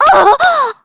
Jane_L-Vox_Damage_kr_04.wav